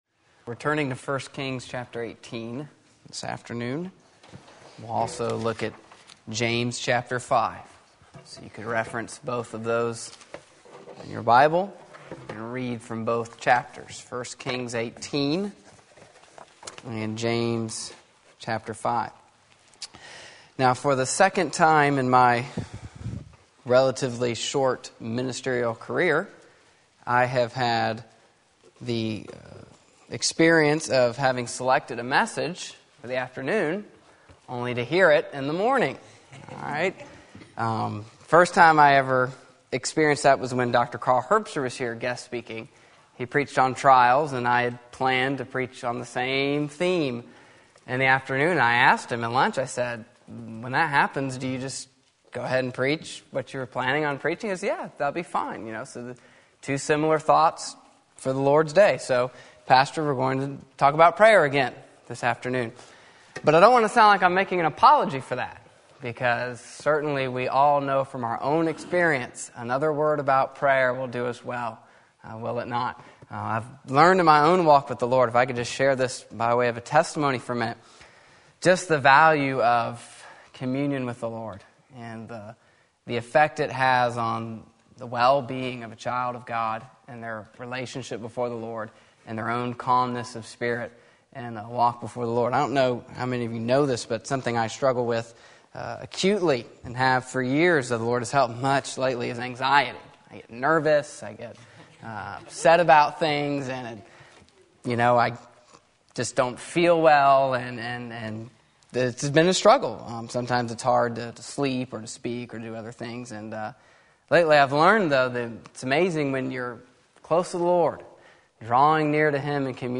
Sermon Link
James 5:16-18 Sunday Afternoon Service